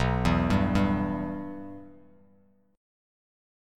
Bsus4#5 chord